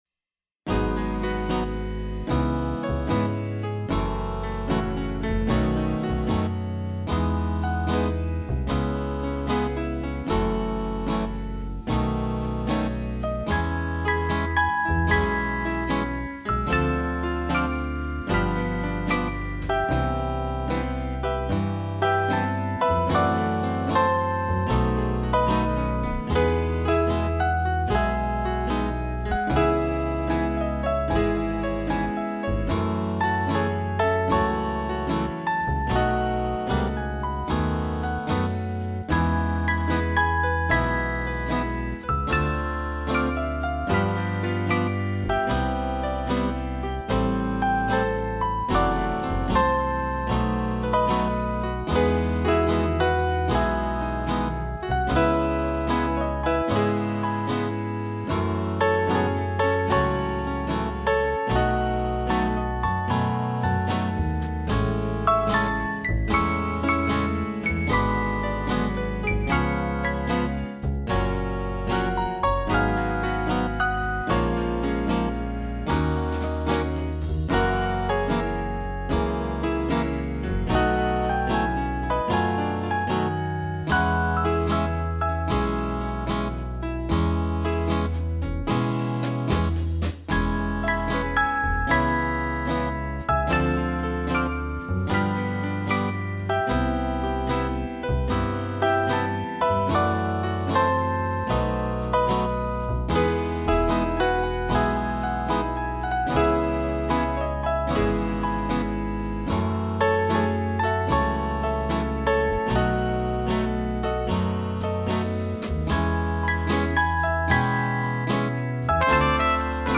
今回は、ピアノがメインのスローなジャズバラードに挑戦してみました。
目指すは、夜、洒落たバーでカクテルを飲みながら聴くのに合いそうな曲です。
スローでスムーズなスウィングジャズ･バラード。
, M-スローストリングス, ドラム JazzBrushes)